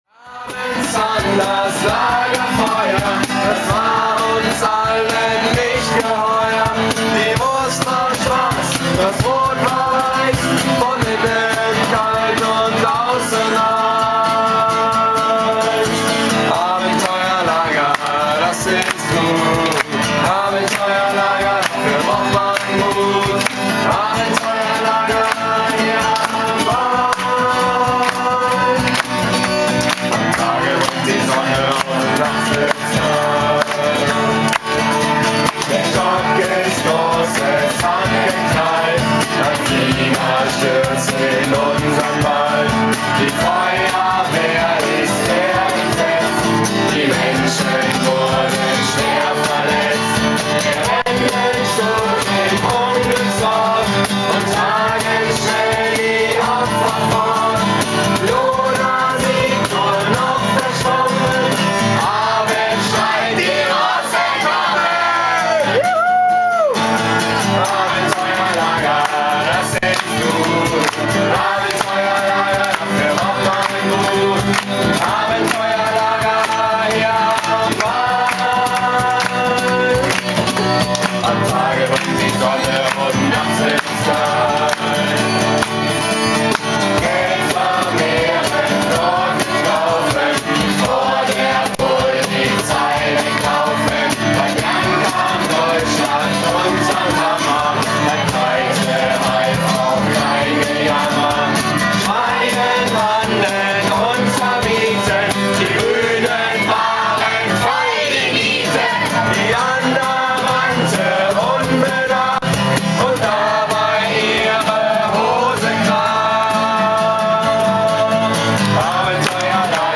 Jeden Tag unseres Abenteuercamps wird eine neue Strophe gedichtet, in der die Highlights des Tages besungen werden.